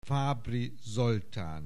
Aussprache Aussprache
FABRIZOLTAN.wav